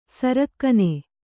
♪ sarakkane